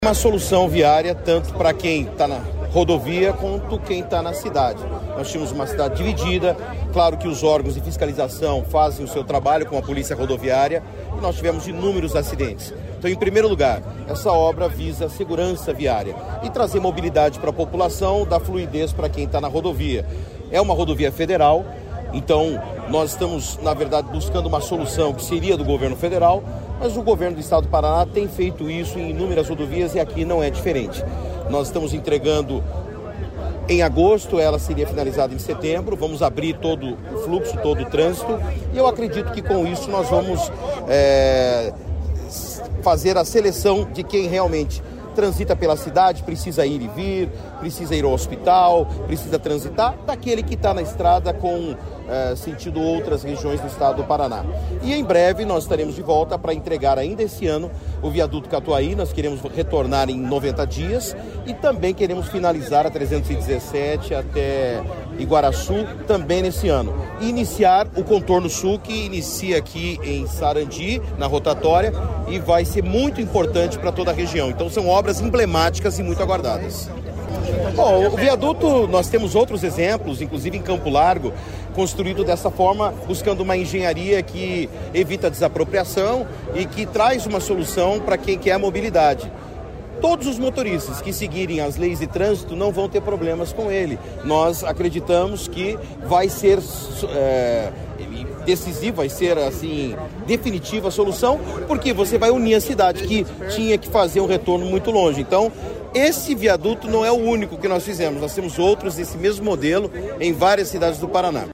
Sonora do secretário Estadual de Infraestrutura e Logística, Sandro Alex, sobre a inauguração dos viadutos em Sarandi sobre a BR-376